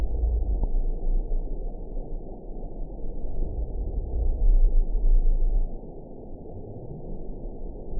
event 922773 date 03/31/25 time 00:24:08 GMT (2 months, 2 weeks ago) score 9.00 location TSS-AB10 detected by nrw target species NRW annotations +NRW Spectrogram: Frequency (kHz) vs. Time (s) audio not available .wav